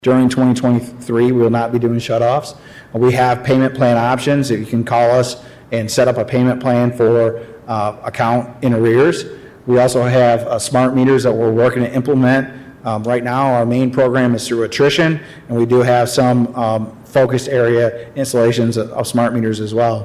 KALAMAZOO, MI (WKZO AM/FM) — Kalamazoo city commissioners held a special session Monday afternoon, August 29, to discuss increases in future water utility rates.